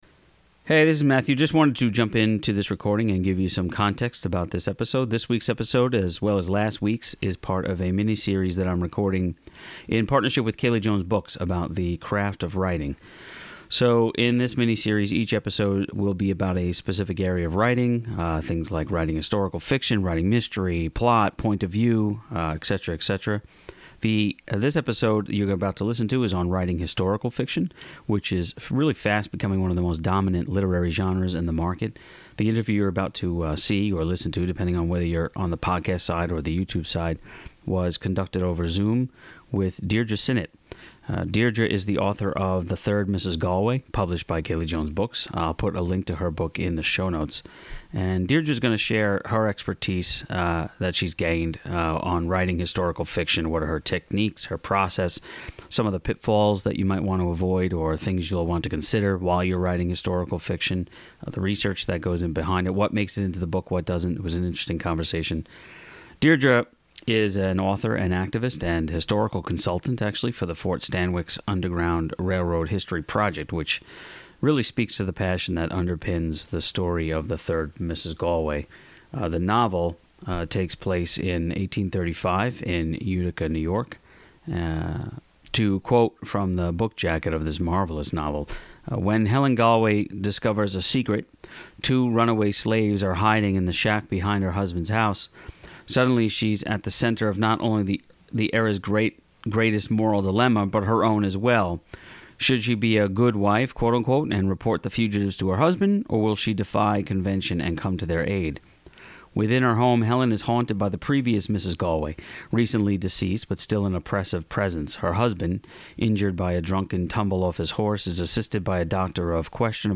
In this "Craft Series" we interview a KJB writer in a discussion that centers on one particular aspect of the craft of writing. From point of view to crafting plot, we break down some of the things writers should consider while working on their projects.